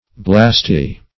Search Result for " blasty" : The Collaborative International Dictionary of English v.0.48: Blasty \Blast"y\, a. 1.